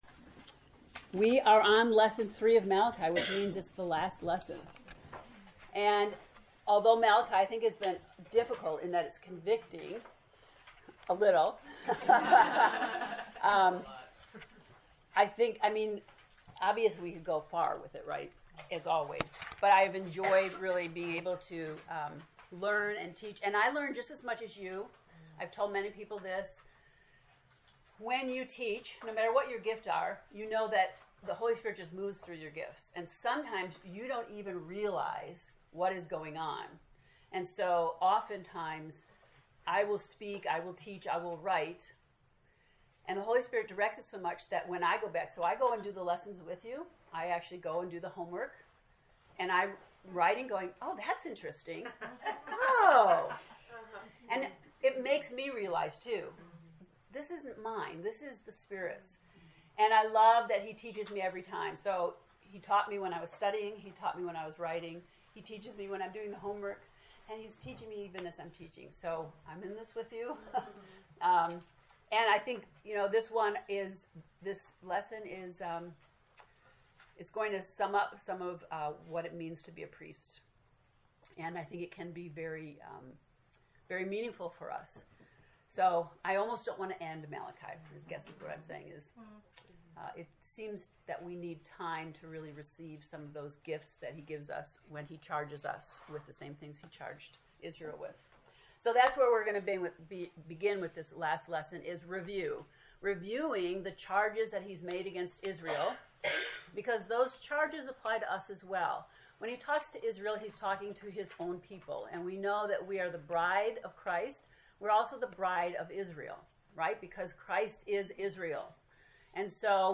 To listen to the lesson 3 lecture, click below: